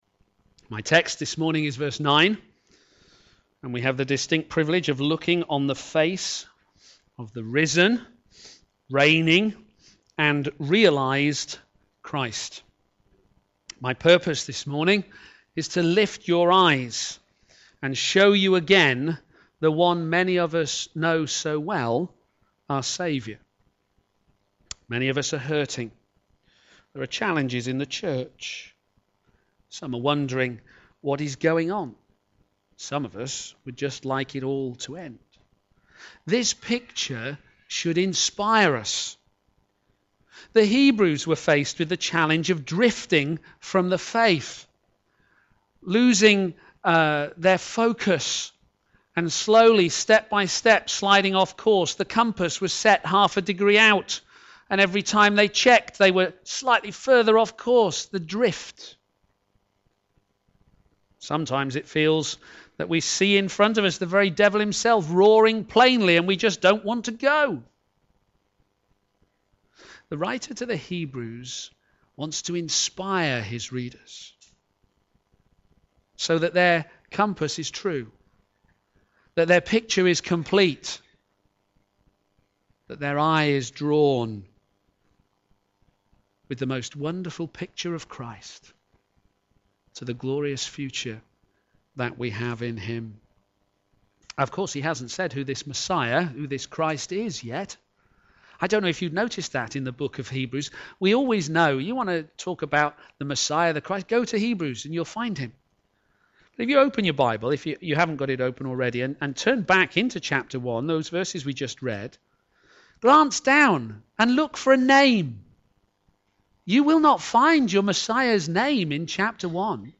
Media for a.m. Service
Theme: Jesus Crowned with Glory Sermon